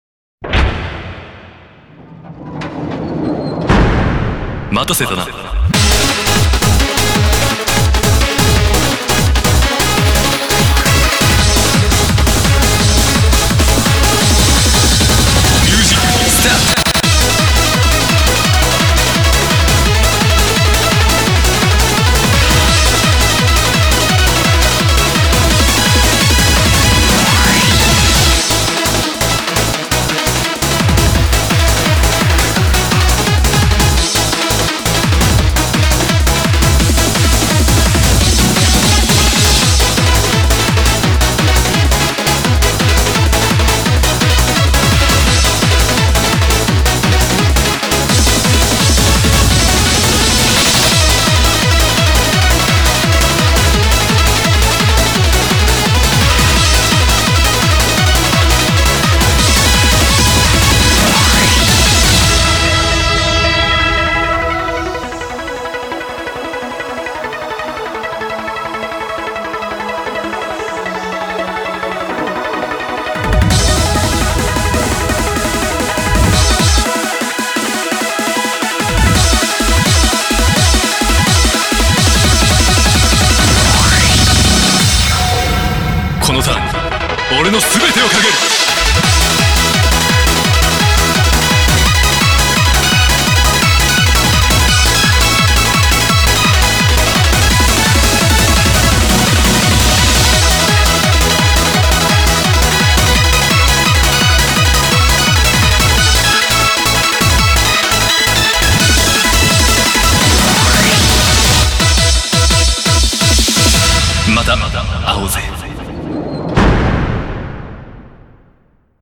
BPM170
Audio QualityPerfect (High Quality)
happy, energetic track